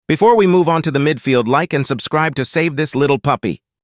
broder Meme Sound Effect
Category: Sports Soundboard